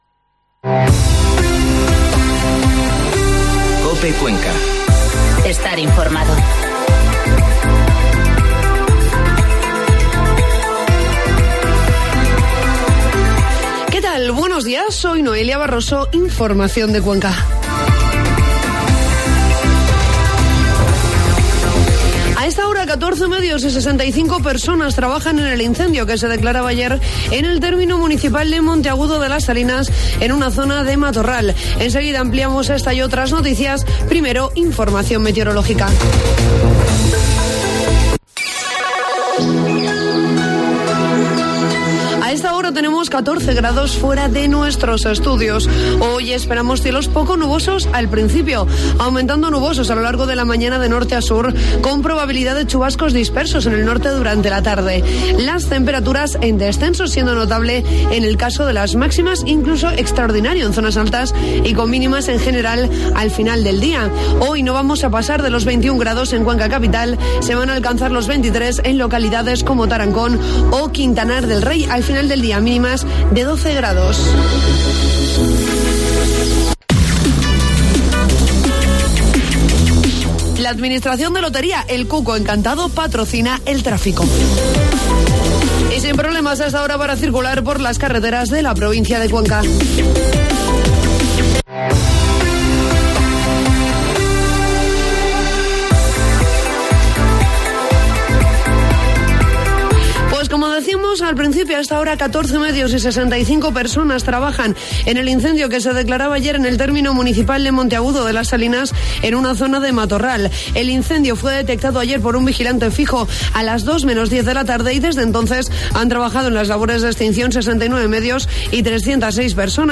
Informativo matinal COPE Cuenca 10 de septiembre